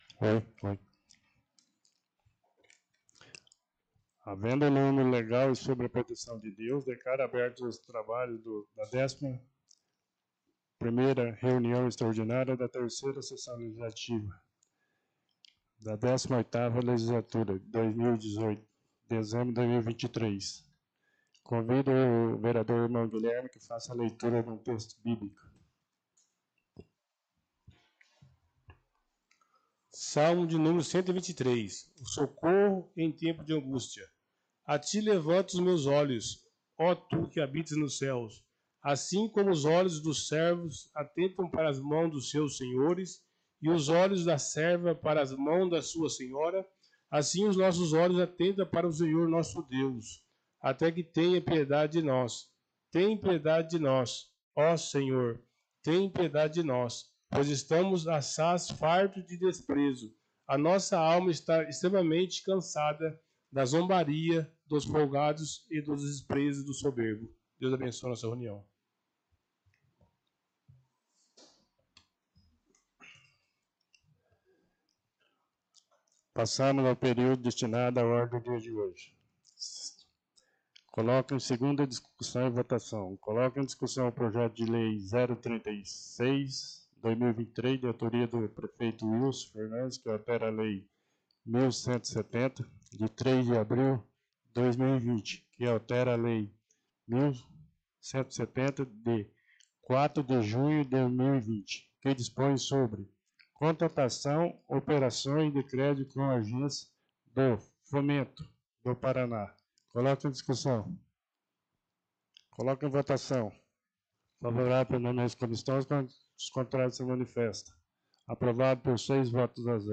Sessões Extraordinárias